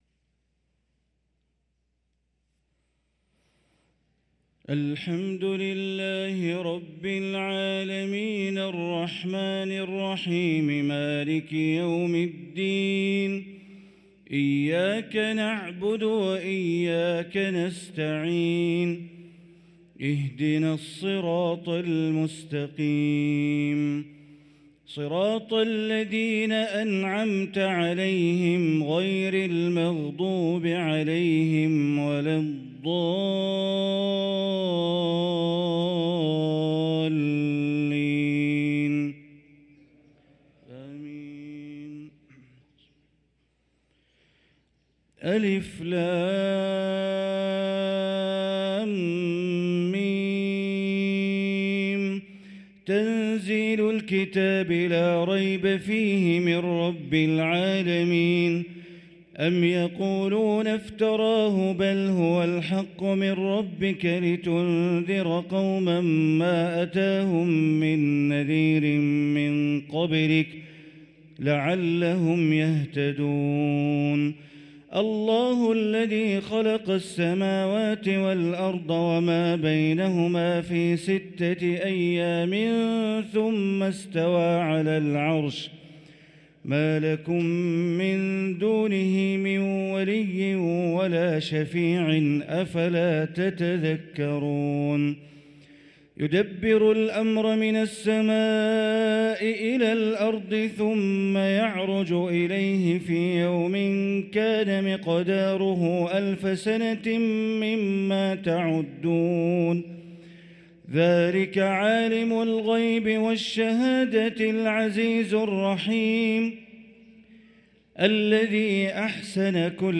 صلاة الفجر للقارئ بندر بليلة 16 صفر 1445 هـ